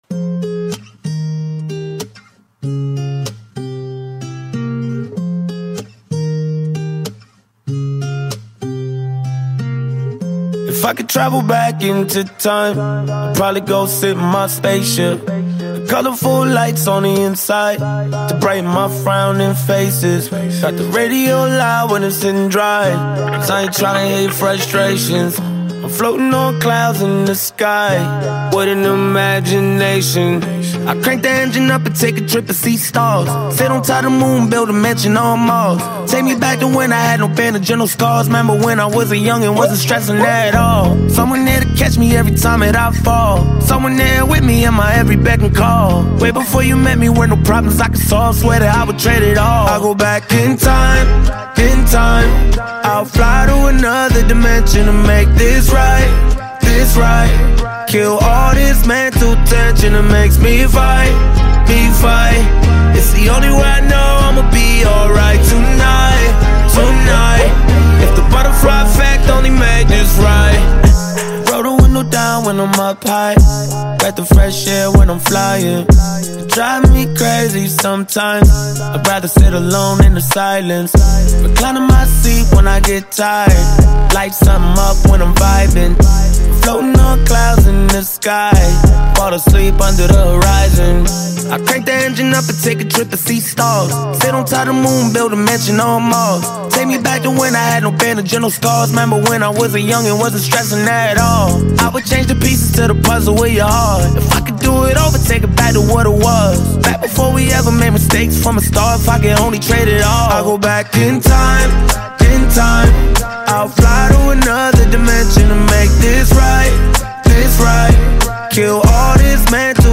Highly gifted vocalist